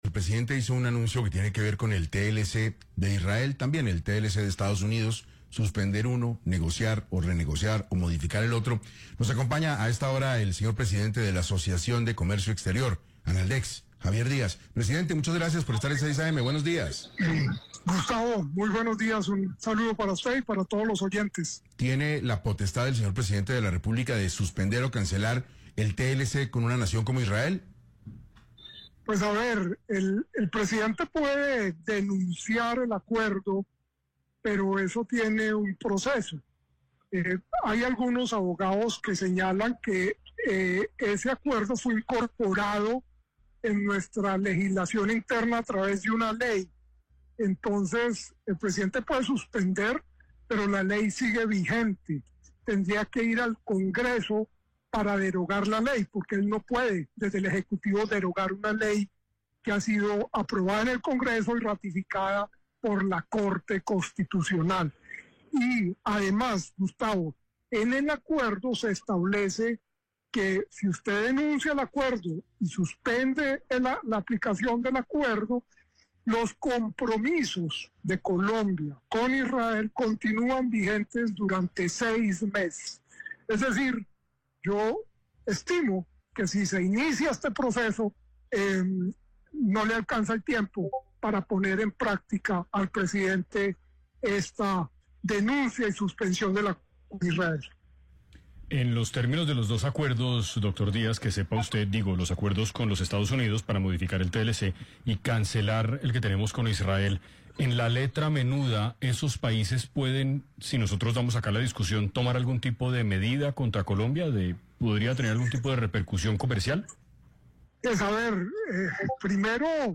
En entrevista con 6AM de Caracol Radio